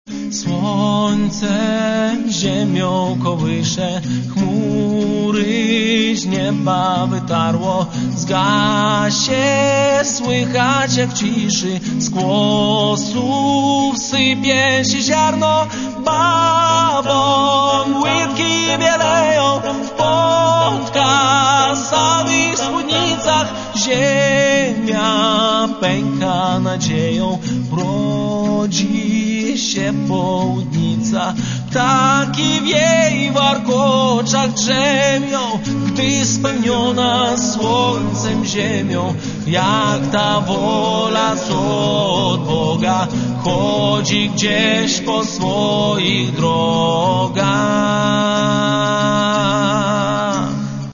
Каталог -> Другое -> Вокальные коллективы